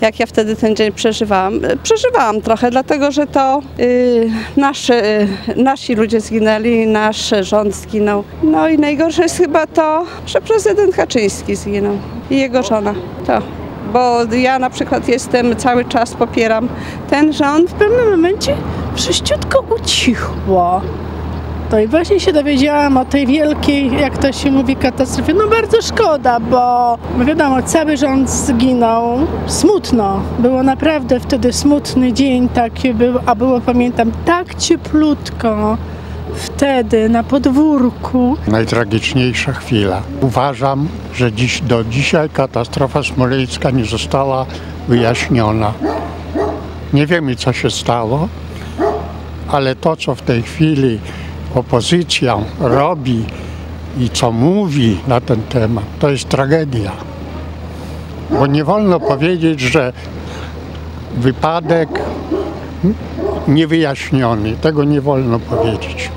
Suwalczanie ze smutkiem wspominają dzień katastrofy.
suwalczanie-o-smolensku.mp3